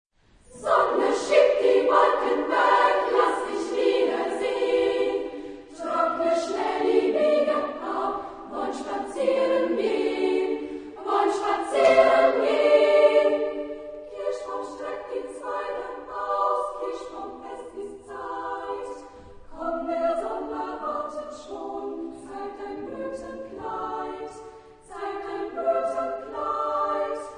Genre-Stil-Form: Zyklus ; Chorlied
Chorgattung: SSAA  (4 Kinderchor ODER Frauenchor Stimmen )
Tonart(en): G-Dur
Aufnahme Bestellnummer: 5.Deutscher Chorwettbewerb, 1998
Lokalisierung : Voix Enfants Profane Acappella